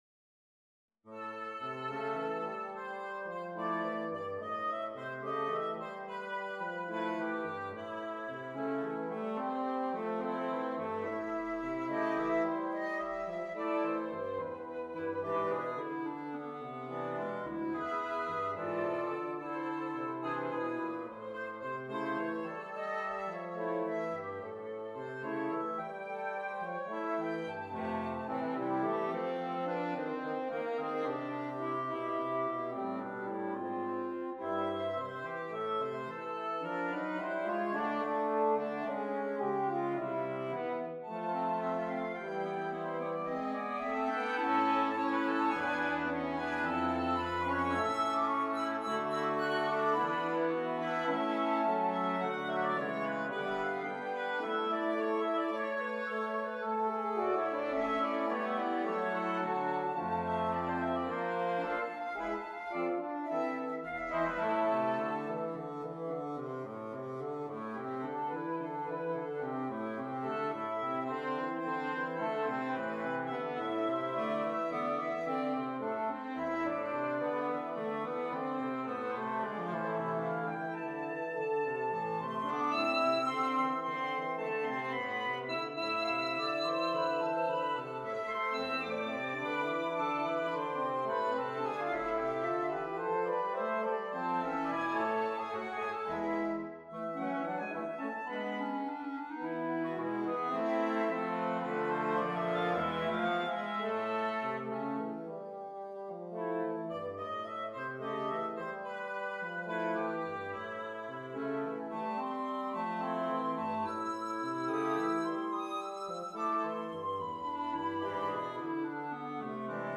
This new version, for woodwind quintet, shares the initial theme and meter but adds 16 measures comprising seventeen percent more notes plus several dozen modulations and compositional maneuvers I hadn’t yet discovered in 1994.
woodwind quintet